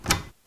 Open Closet (Louder).wav